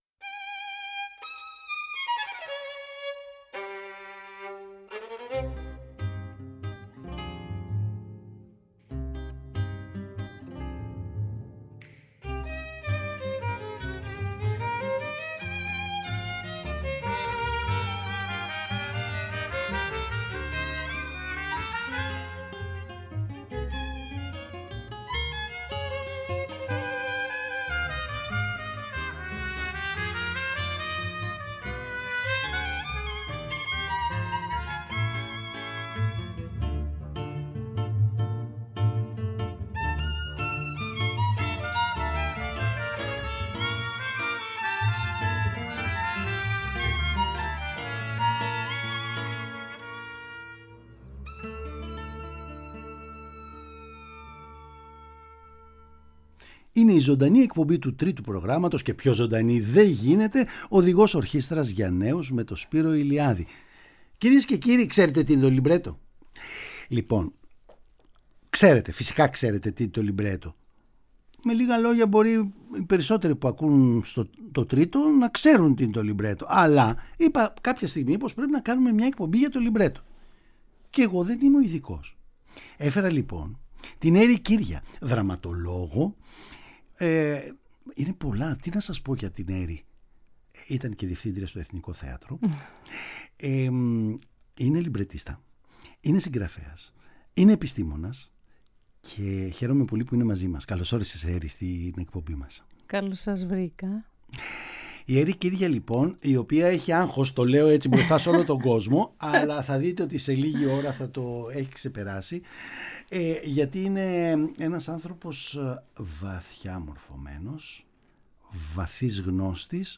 Τι είναι το λιμπρέτο, πώς γράφεται, πως καθορίζει τη μουσική, πώς συνεργάζεται ο λιμπρετίστας με τον συνθέτη και πολλά άλλα θέματα θα συζητηθούν με το γνωστό πάθος αλλά χωρίς υπερβολή. Με λίγα λόγια: μια απέριττη συζήτηση για το λιμπρέτο